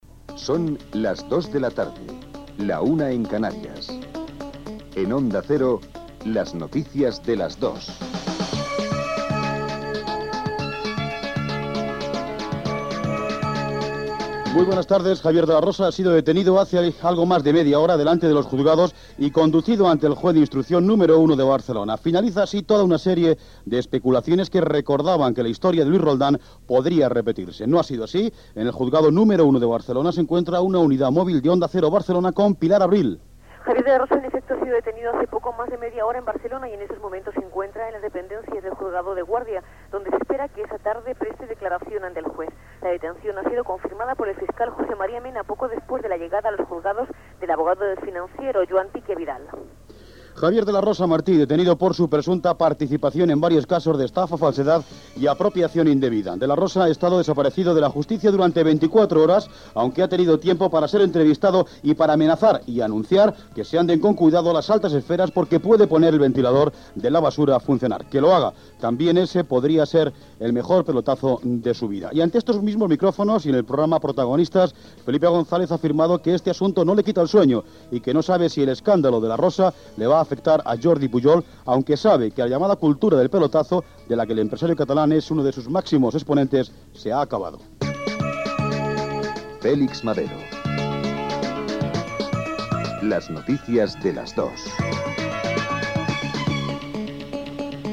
Careta del programa i primera notícia: la detenció de l'empresari Javier de la Rosa per casos d'estafa.
Informatiu